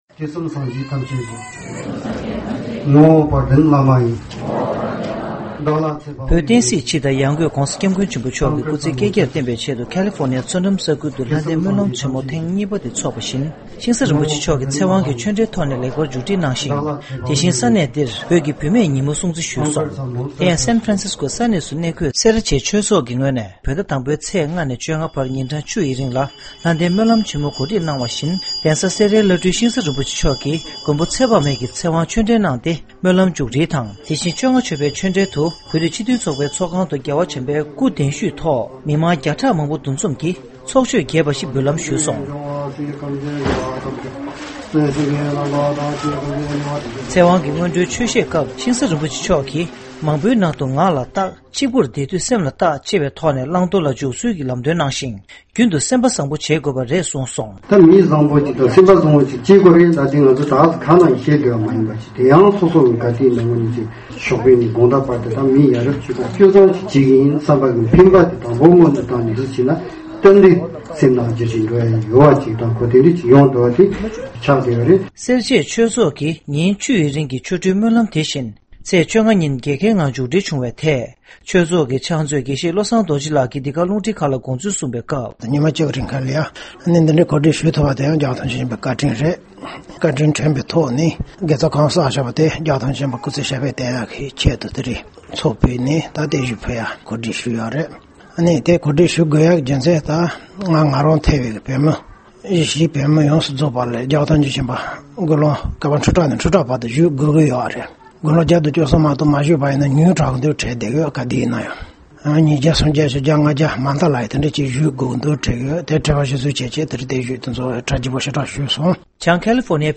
བོད་ཀྱི་བུད་མེད་ཉིན་མོ་སྲུང་བརྩི་ཞུས་པ། གཞུང་འབྲེལ་མཛད་སྒོའི་ཐོག་སྲིད་སྐྱོང་བློ་བཟང་སེང་གེ་མཆོག་གིས་བཀའ་ཤག་གི་གསུང་བཤད་གནང་བ།